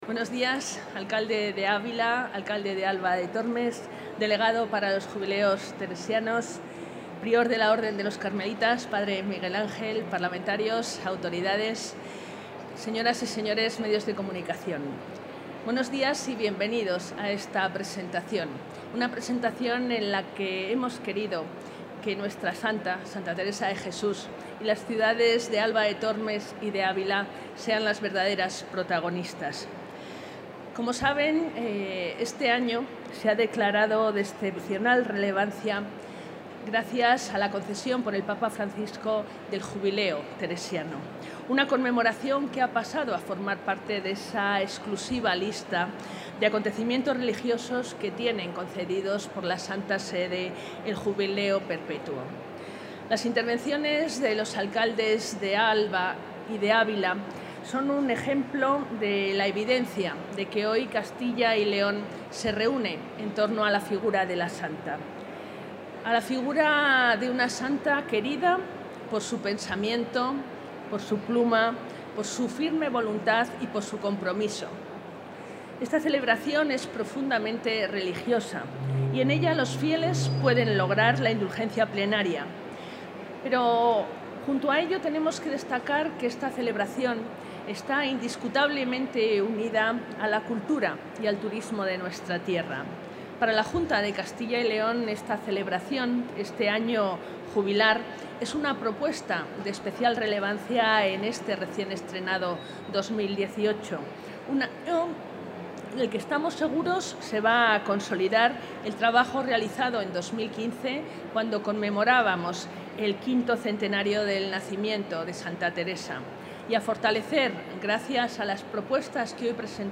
Consejera de Cultura y Turismo.
La Junta ha presentado en el marco de la Feria Internacional de Turismo Fitur la oferta turística y cultural, diseñada en colaboración con los ayuntamientos de Ávila y Alba de Tormes, con motivo de la celebración del primer Año Jubilar Teresiano. El programa ‘Un año con Teresa’ conmemora en la ciudad de Ávila este acontecimiento con diversas actividades culturales, que han comenzado en el mes de octubre de 2017 y que continuarán a lo largo del año 2018.